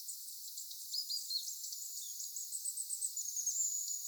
ilmeisesti erikoinen kuin
pyrstötiaisen pieni säe
ilmeisesti_erikoinen_pyrstotiaisen_kuin_pieni_sae.mp3